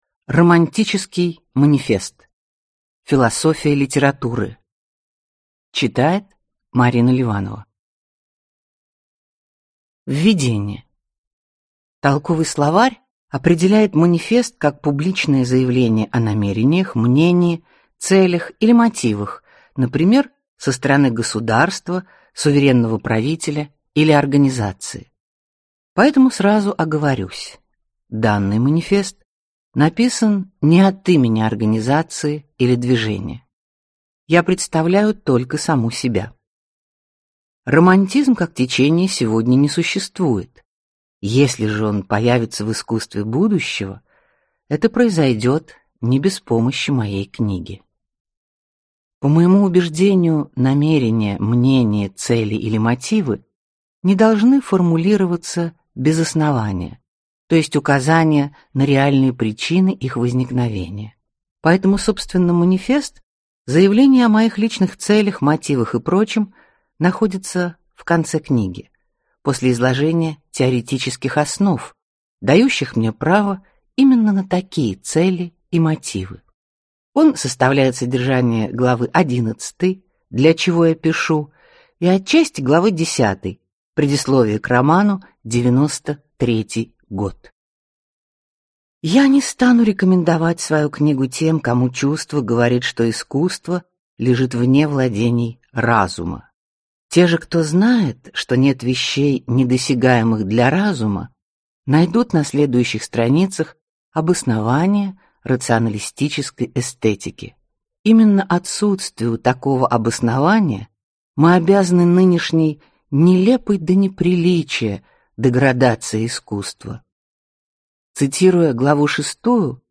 Аудиокнига Романтический манифест. Философия литературы | Библиотека аудиокниг